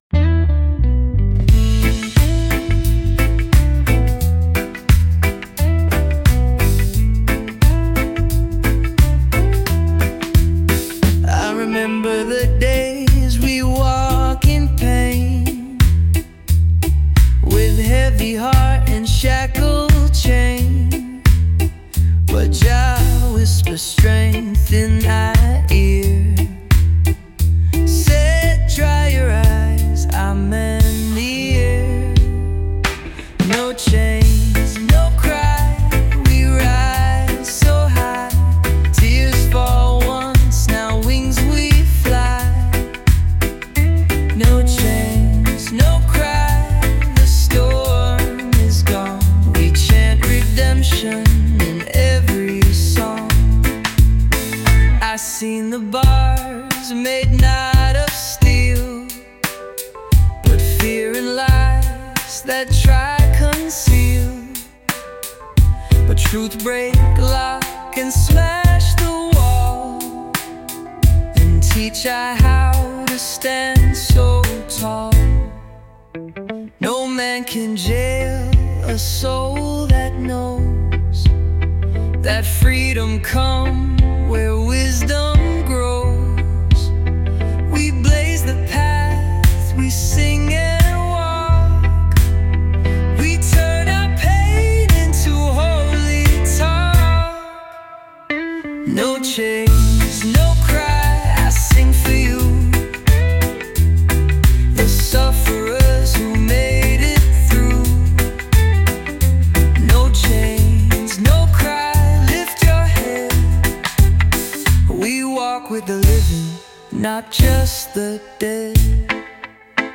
- From soulful reggae to chill beats
🌴 Chilling out with deep basslines